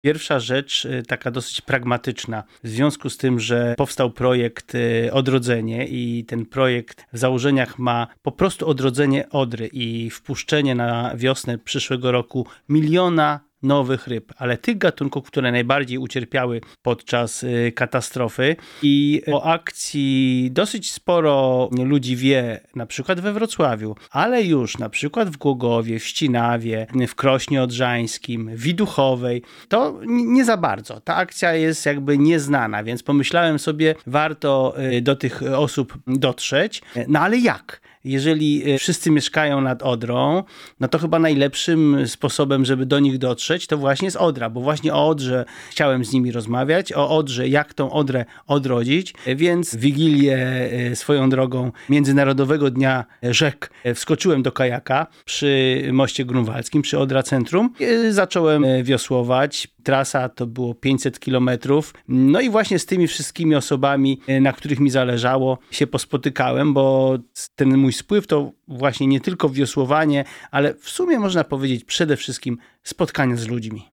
Mówi ekolog